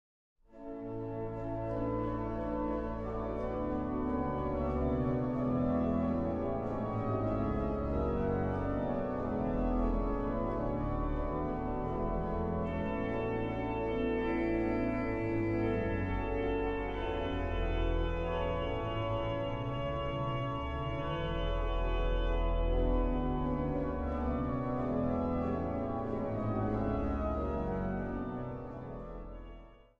improvisatie en klassieke werken
König-orgel in de St. Stevenskerk te Nijmegen